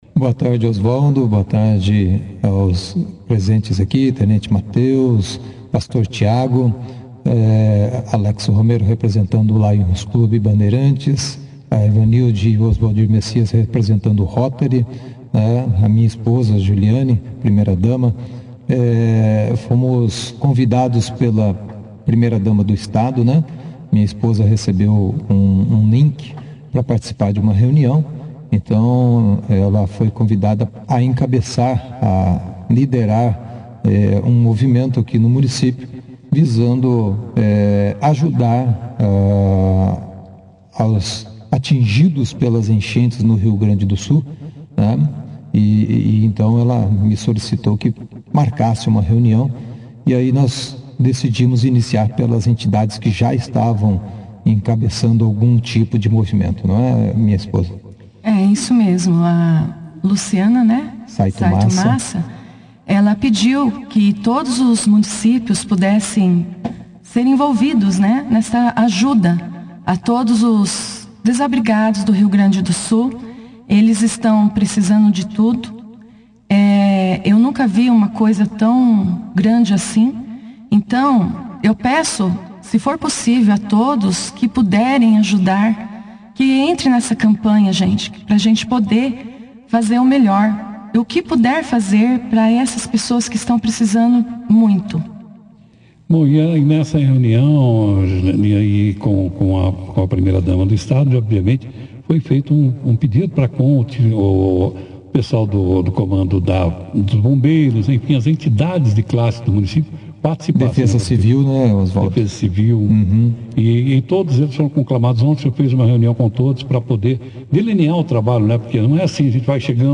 Durante a entrevista foi dado detalhes da campanha, que tem como objetivo a arrecadação de itens essenciais para as vítimas das enchentes, incluindo água potável, alimentos não perecíveis, material de limpeza, produtos de higiene pessoal, colchões, cobertores e até mesmo ração animal para os animais afetados.